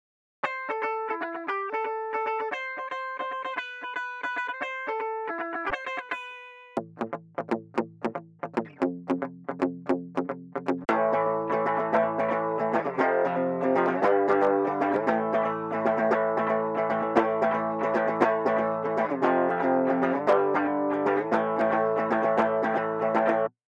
Проверил сегодня, поиграл, выпадения в моно периодические, и вообще впечатление, что вправо-влево по панораме кидает. В общем на дабл не похоже.